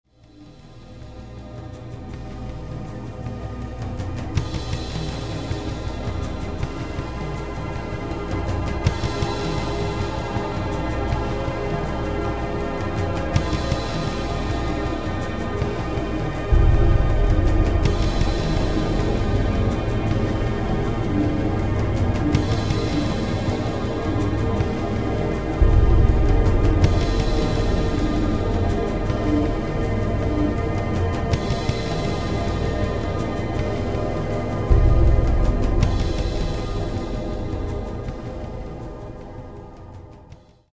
listeners with its hypnotic musical charm.